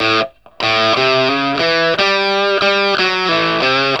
WALK1 60 A.A.wav